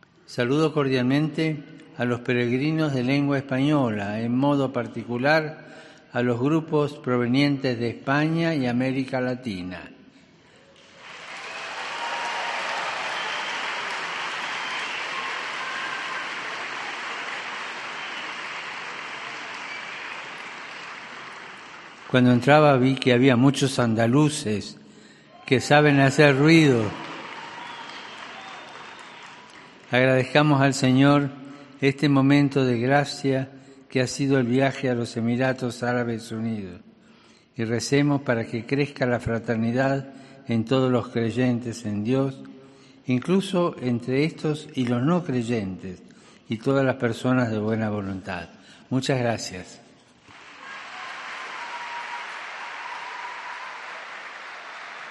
El Papa ha querido dedicar un saludo especial a los peregrinos provenientes de España tras un estruendoso aplauso
Al final de su resumen en español, ha saludado a los peregrinos de habla hispana, y el público se ha roto en aplausos.
A lo que los andaluces presentes, han vuelto a responder con un fuerte aplauso.